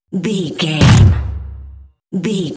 Dramatic hit deep electronic wood
Sound Effects
Atonal
heavy
intense
dark
aggressive